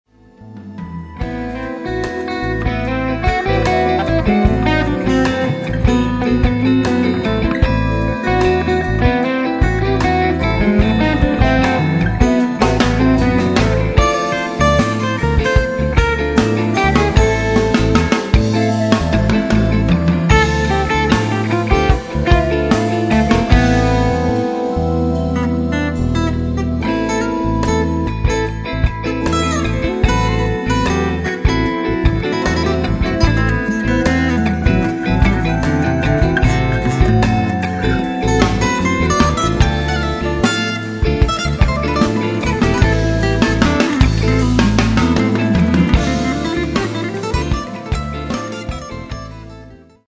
Experimental Rock Three Piece
- compositions, vocals, guitar
- drums
- bass, keyboards, guitar
recorded at Midtown Recording Studio